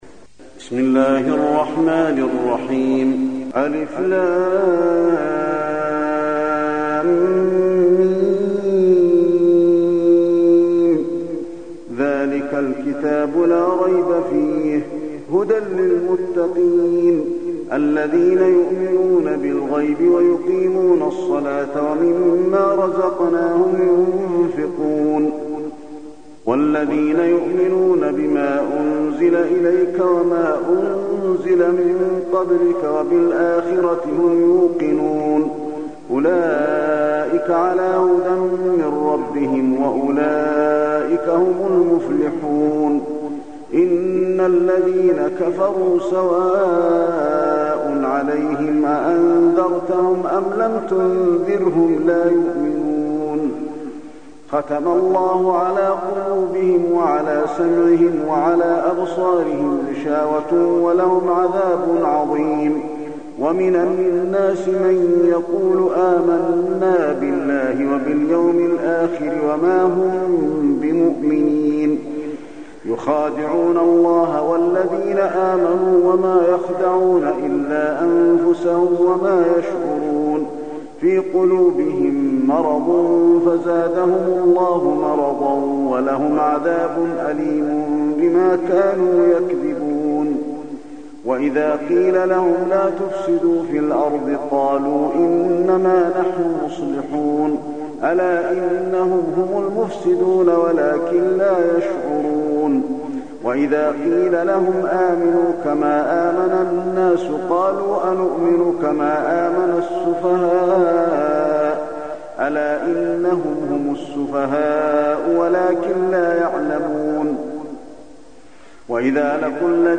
المكان: المسجد النبوي البقرة